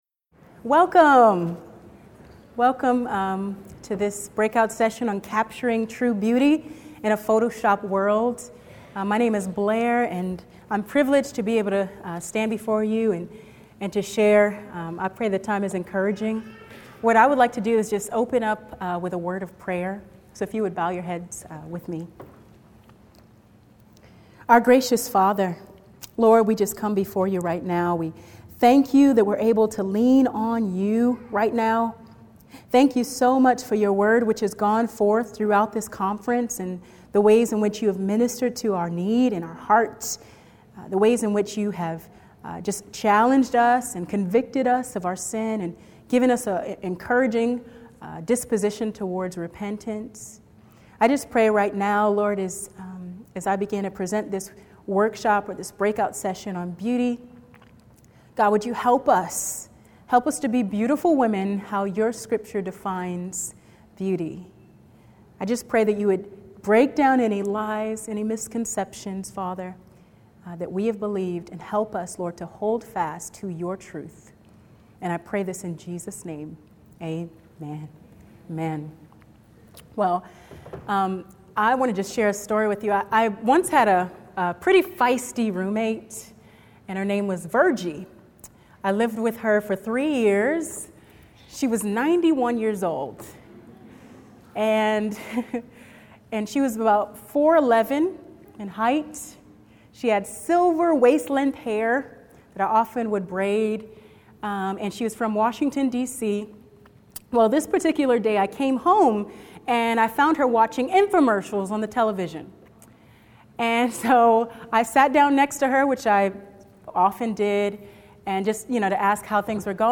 Capturing True Beauty in a Photoshopped World | True Woman '14 | Events | Revive Our Hearts
In this workshop we will consider God’s definition of beauty and look at how to find our identity in the Lord rather than in the mass media.